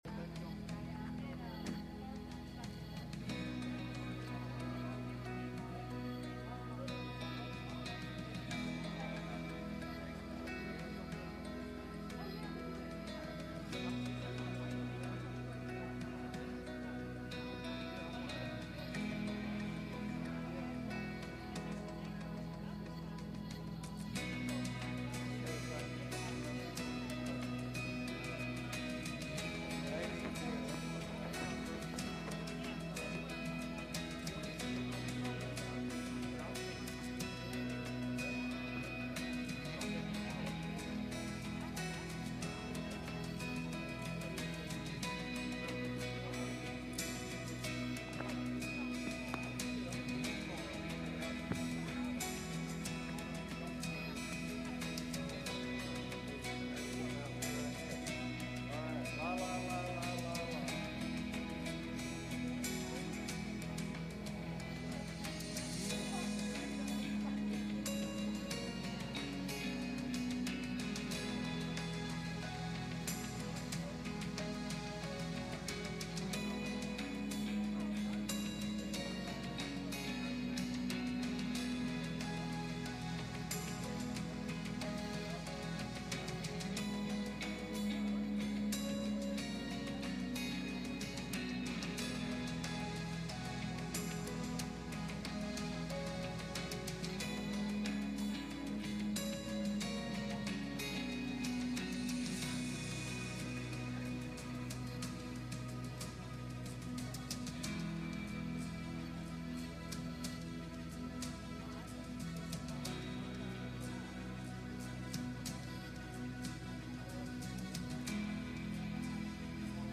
Matthew 6:33 Service Type: Midweek Meeting « Spiritual Maturity